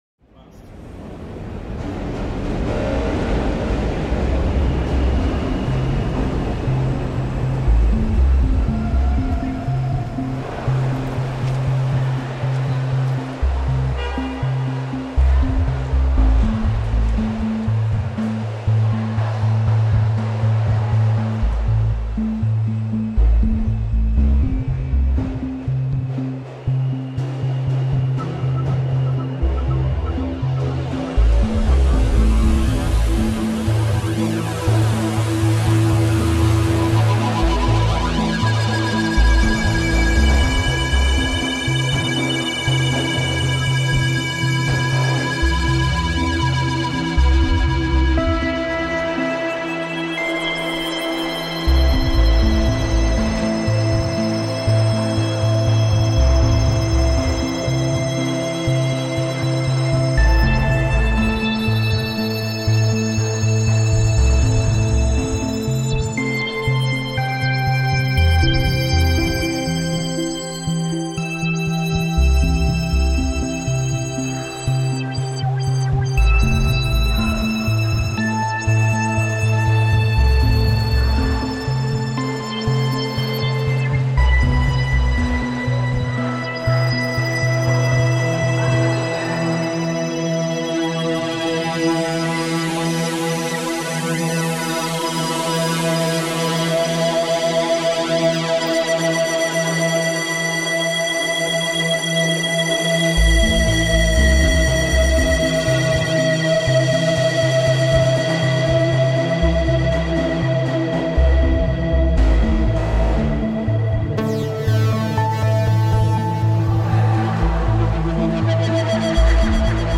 Chinatown train recording reimagined